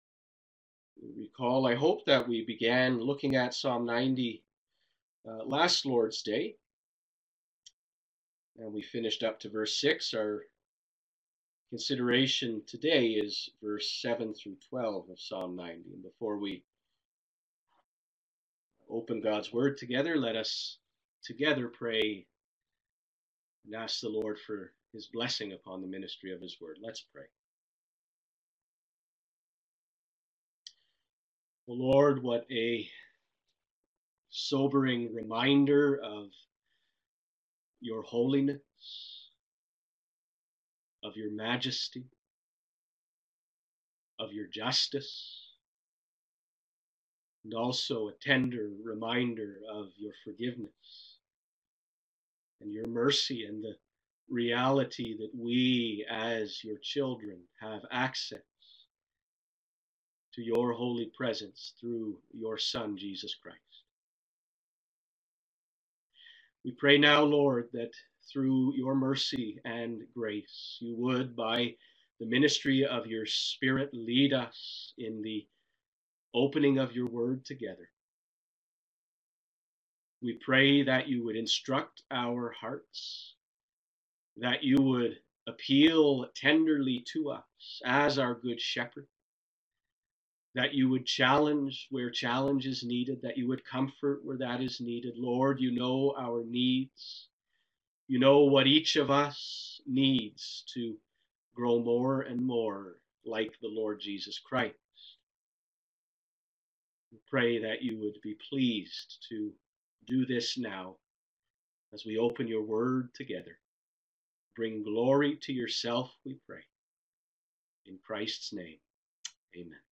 Godly Wisdom in a Time of Crisis – PART 2 (Psalm 90) *Live-streamed Recording*